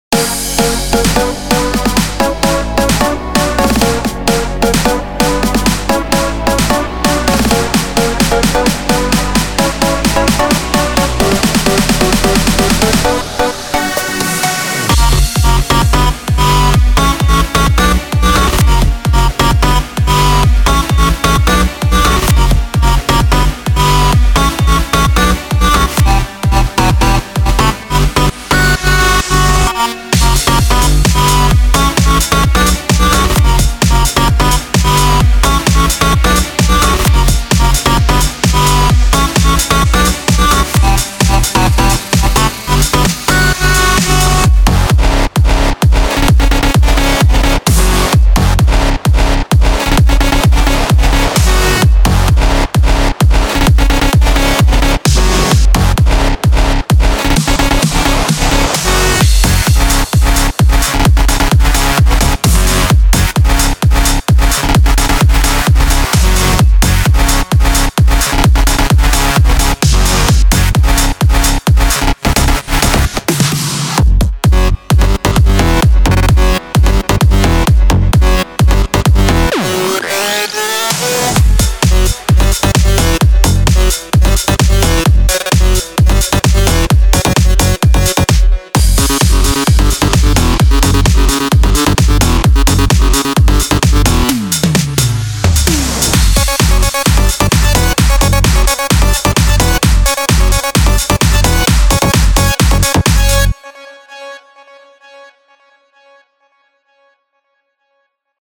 מקצבים לPA 700
סופסוף סולו בועט עם סאונדים חדים, הבאת ביצוע!